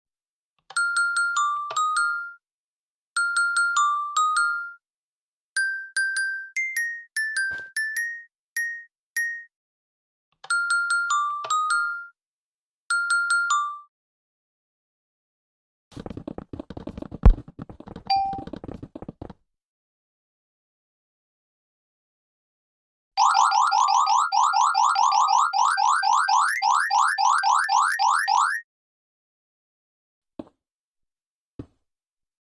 Note Blocks